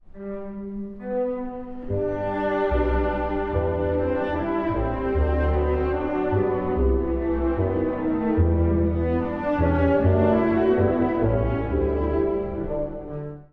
↑古い録音のため聴きづらいかもしれません！（以下同様）
このテーマが、形を変えながら何度も登場します。
ときに牧歌的に、ときにスラブ舞曲のように、音楽が進んでいきます。